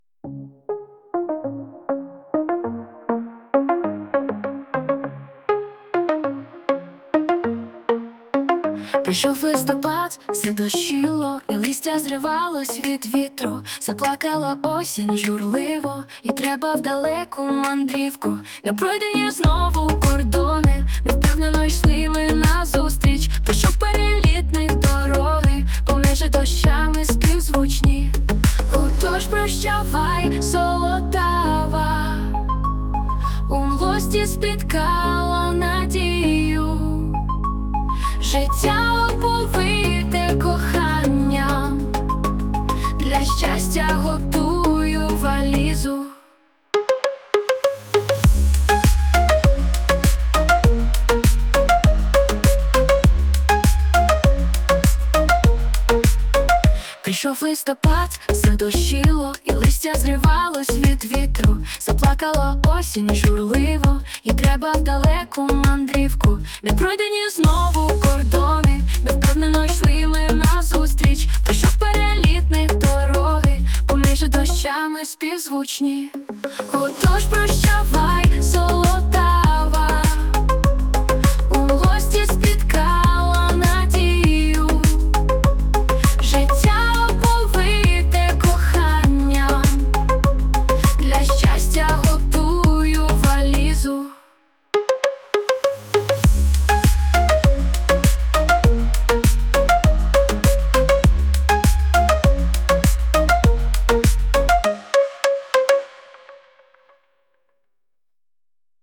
СТИЛЬОВІ ЖАНРИ: Ліричний
Дуже дякую за чудовий коментар, я хотіла б так співати, та це штучний інтелект проспівав. 16 16 16